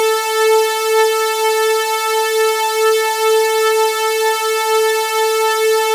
supersaw-test_detune30_mix10.wav